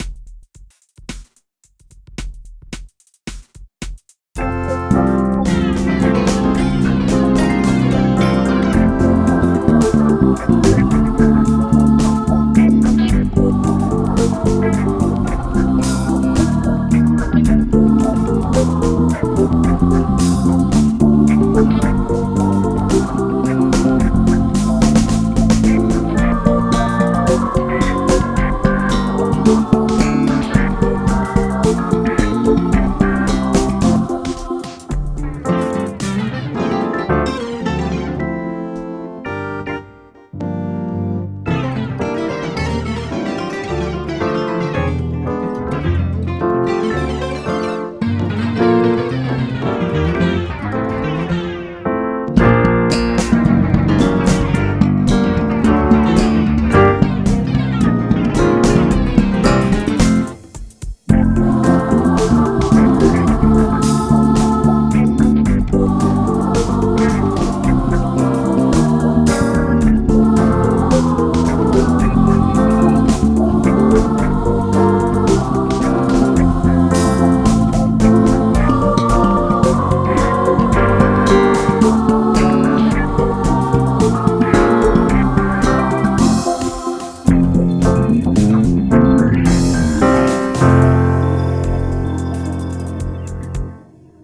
Deux versions instrumentales